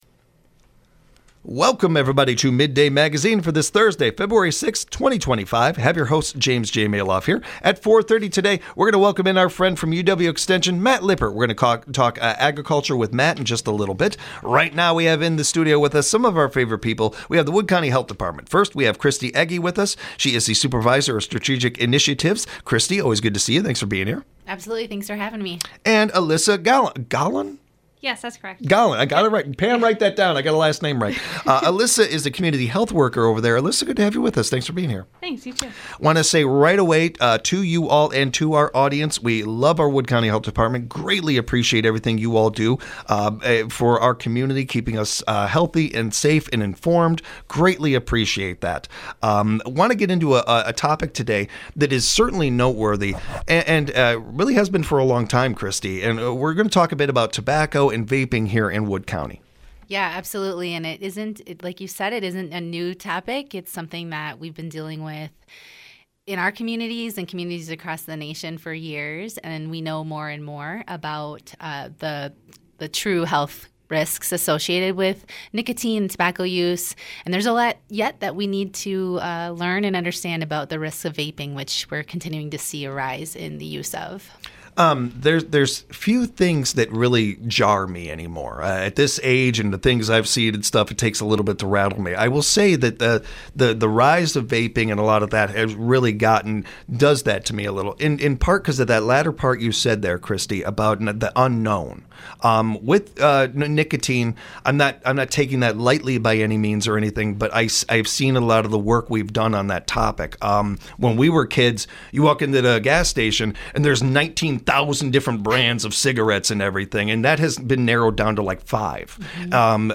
Mid-day Magazine gives you a first look into what’s happening in the Central Wisconsin area. WFHR has a variety of guests such as non-profit organizations, local officials, state representatives, event coordinators, and entrepreneurs.